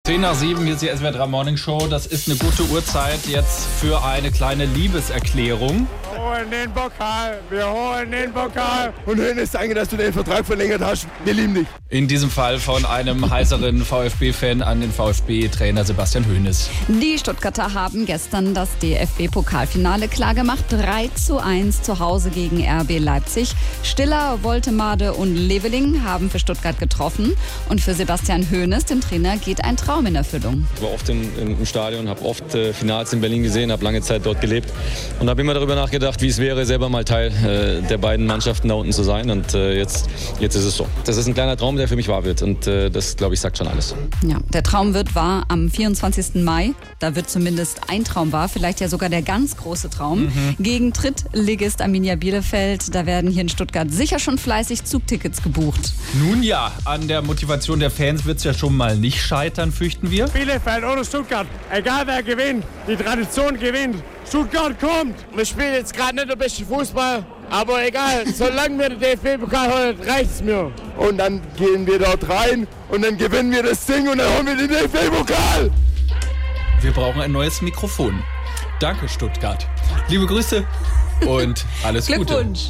Genau wie die euphorischen Fans des VfB Stuttgart – wenn auch ein wenig heiser.